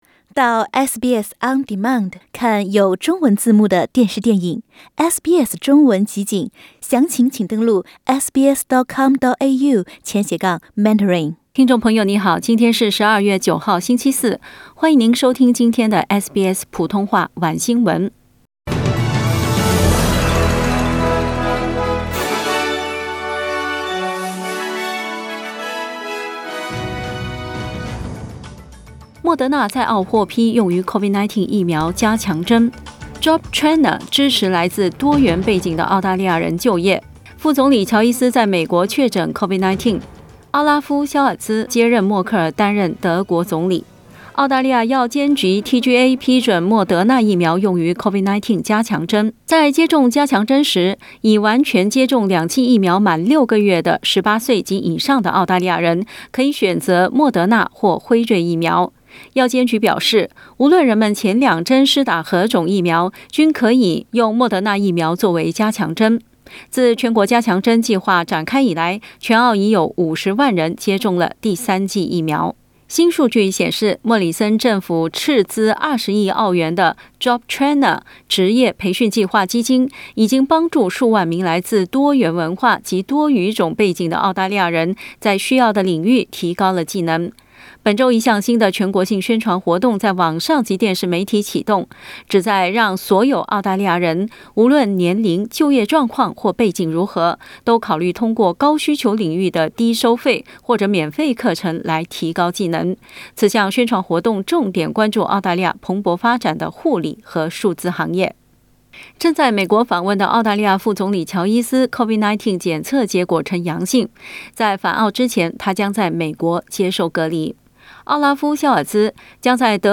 SBS晚新聞（2021年12月9日）
SBS Mandarin evening news Source: Getty Images